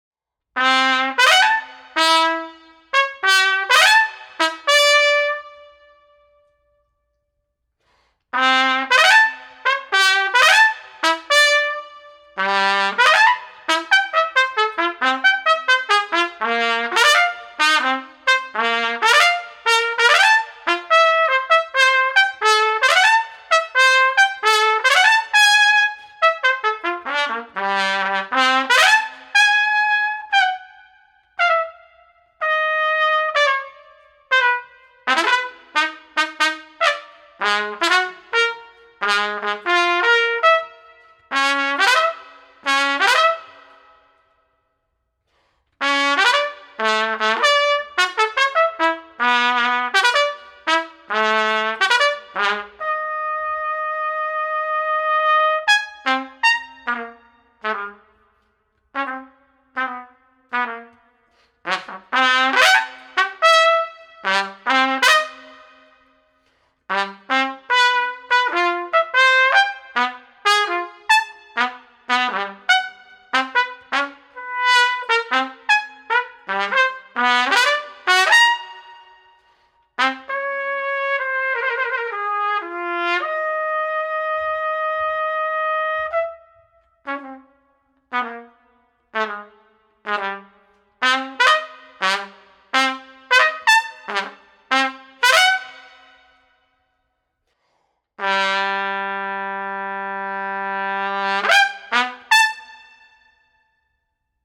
trumpet